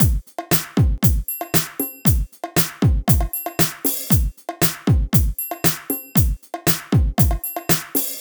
11 Drumloop.wav